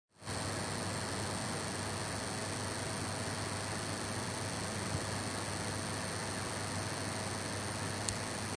Работа видеокарты MSI R9 270X Gaming 2G ITX в автоматическом режиме управления оборотами вентилятора.